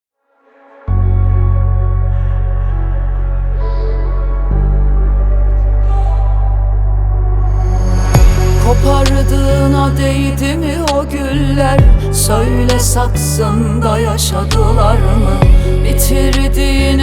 En Güzel Pop Müzikler Türkçe + Yeni şarkılar indir